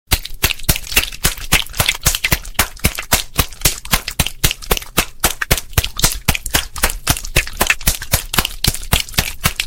Sound Effects
Clap Wet